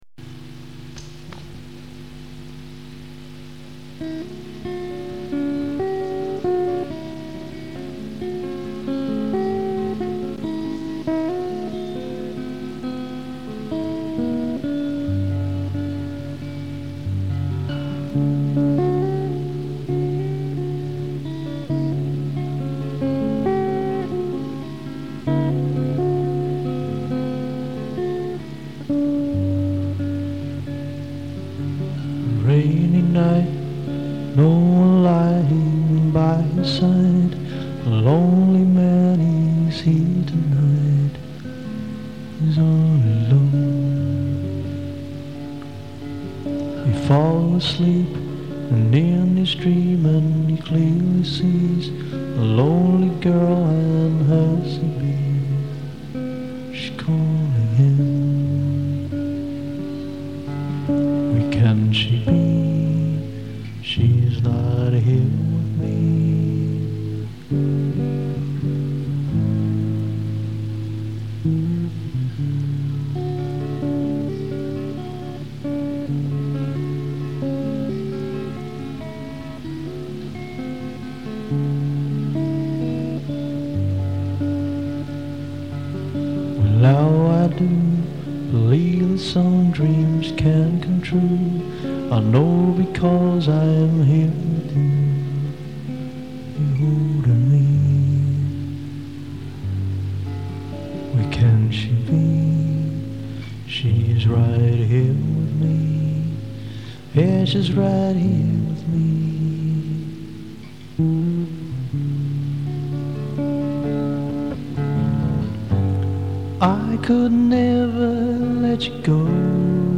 These are cassette recordings as far back as 1987.
So the quality may not be top-notch, but reasonable.